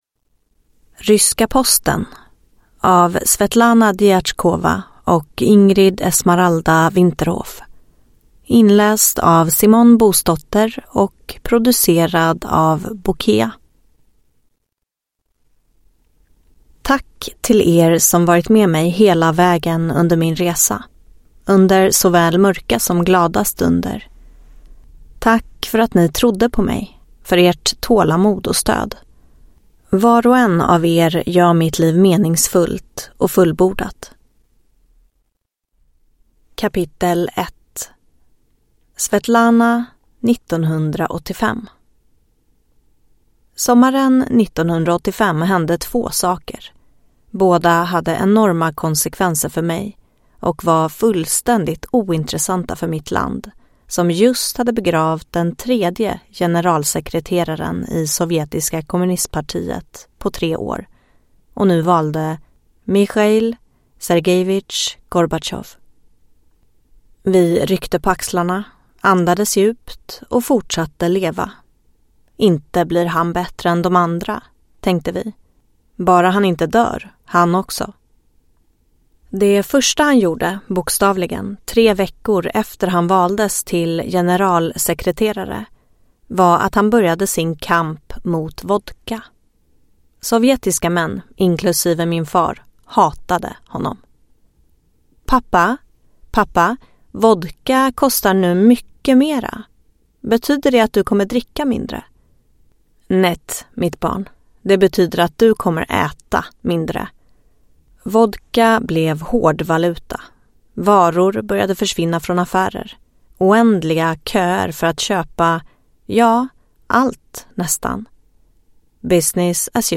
Ryska posten (ljudbok) av Svetlana Diatchkova